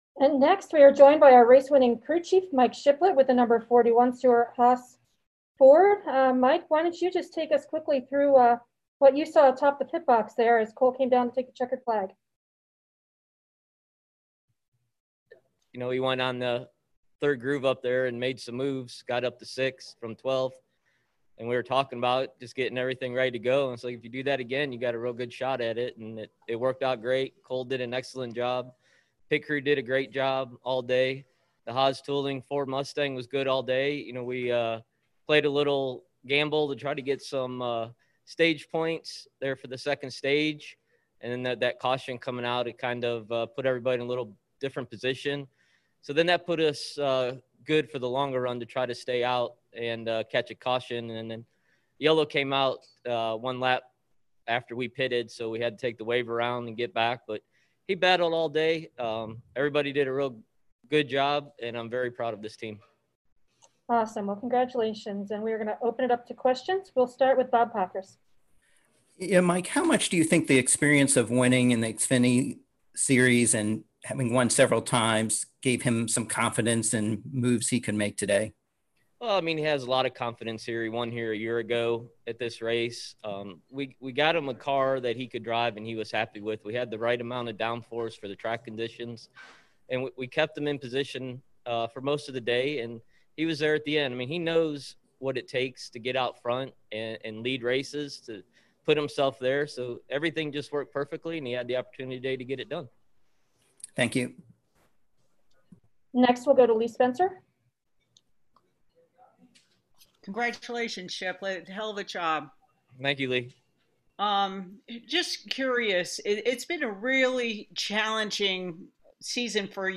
Interviews: